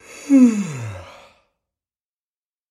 Звуки потягивания
Звук потягиваний при зевании